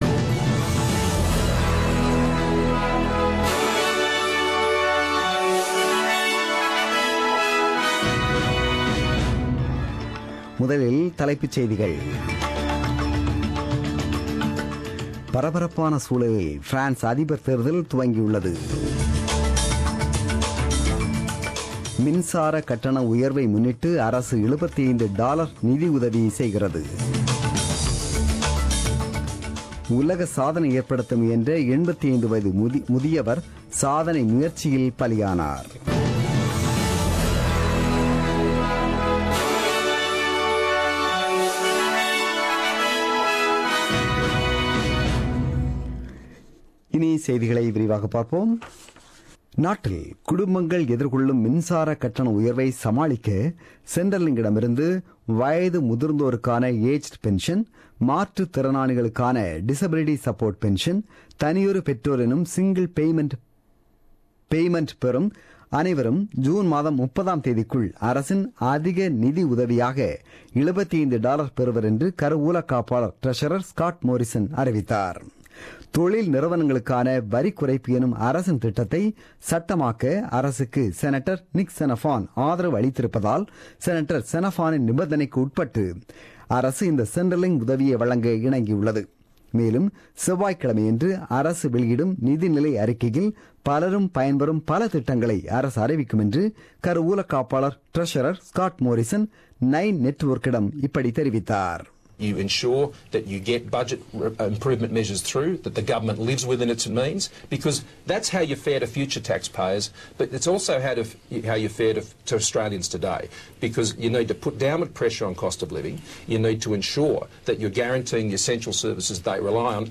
The news bulletin broadcasted on 7 May 2017 at 8pm.